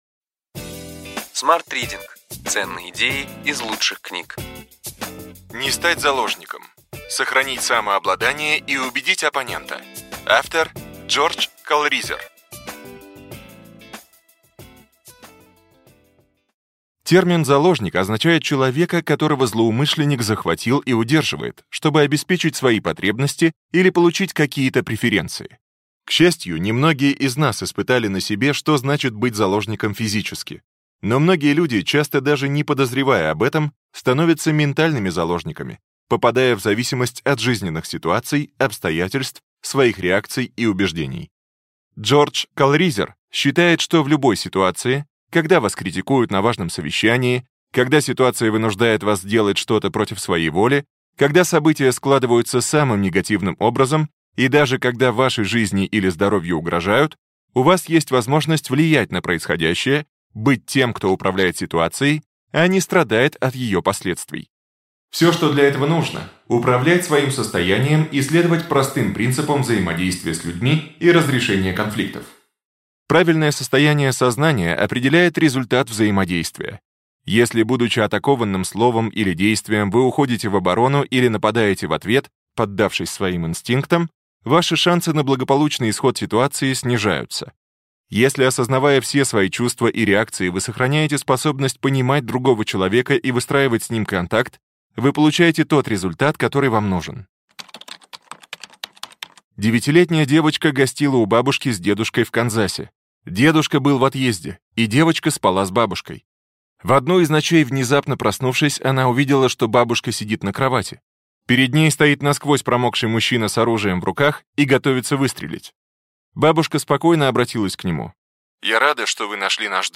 Аудиокнига Ключевые идеи книги: Не стать заложником. Сохранить самообладание и убедить оппонента.